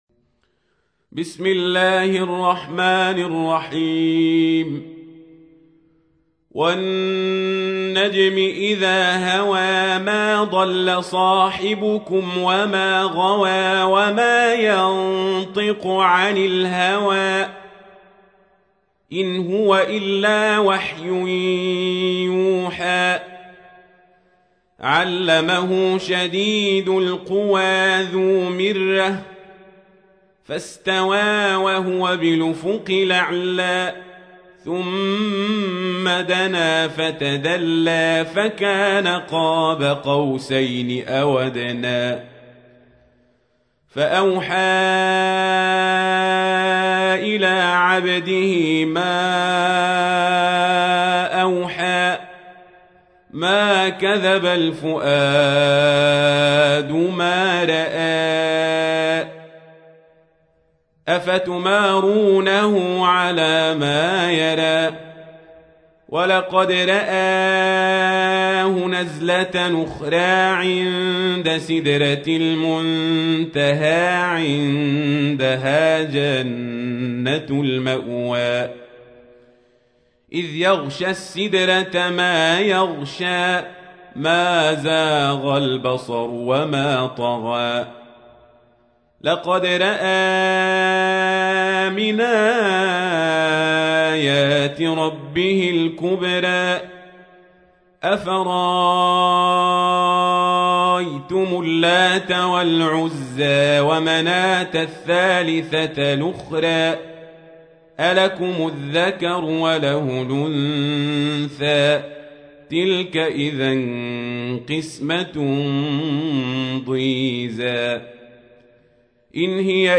سورة النجم / القارئ القزابري / القرآن الكريم / موقع يا حسين